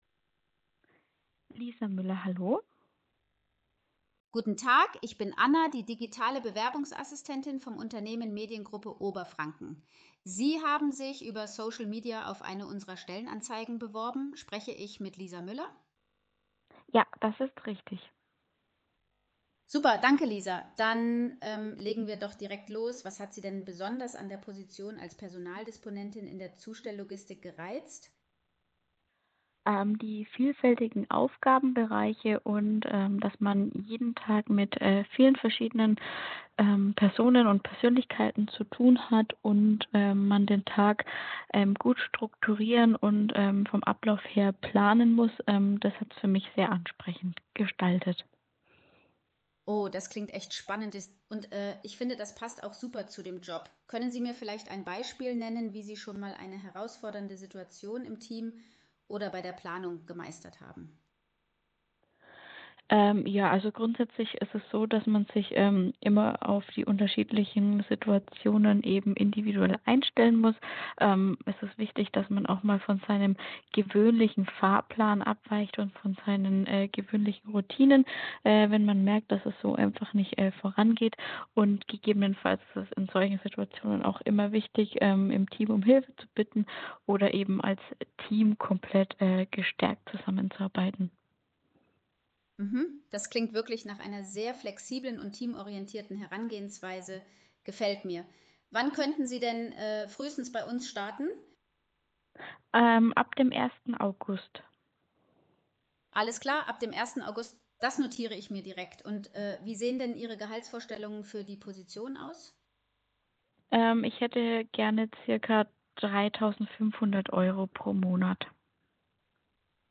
KI-Voicebot-Telefonat
Überzeugen Sie sich selbst von Annas natürlicher Gesprächsführung
Simulationsgespraech-2.mp3